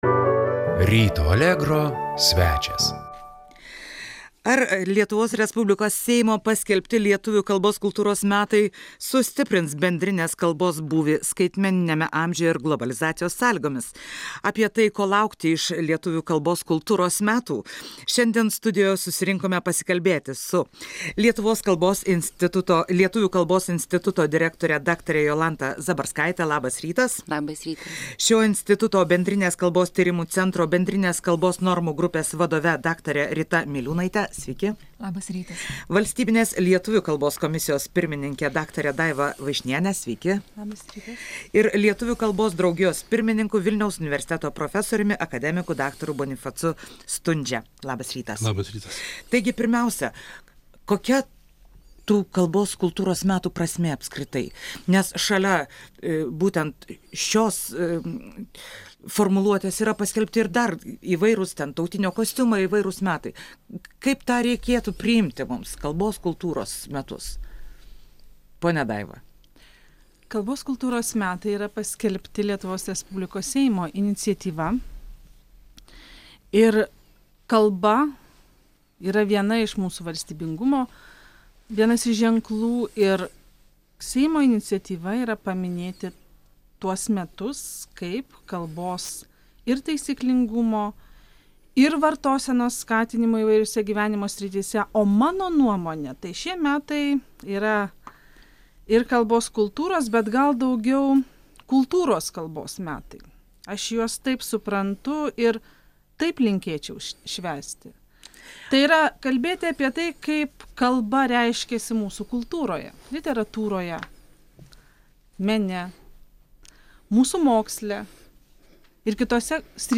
Pokalbis studijoje